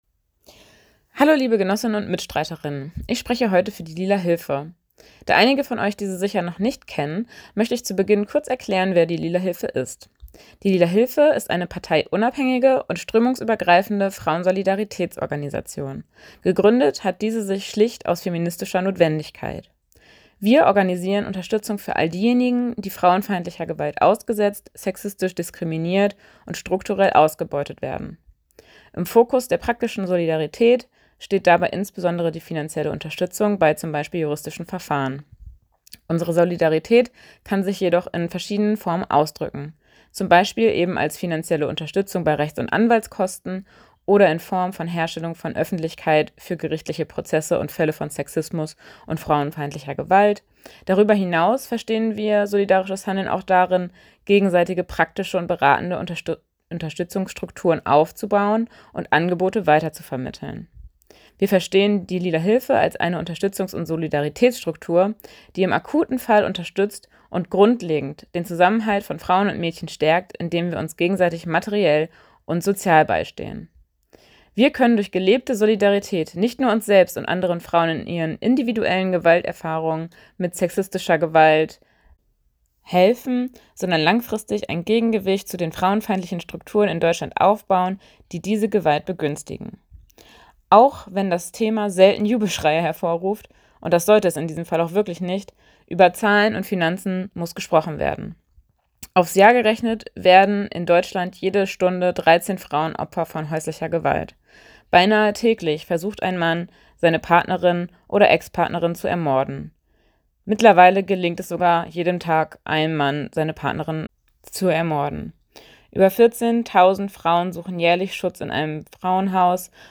Speeches
Am 25. November, dem Internationalen Tag gegen Gewalt gegen Frauen, nahmen wir als Lila Hilfe in Form eines (digitalen) Redebeitrags auf einer Kundgebung in Rostock teil, um auf die Missstände in Bezug auf sexistische Gewalt aufmerksam zu machen.